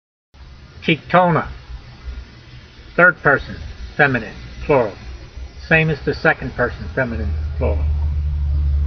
My reading (voice) in modern Israeli style is only good enough to get you started.
teek-tol-na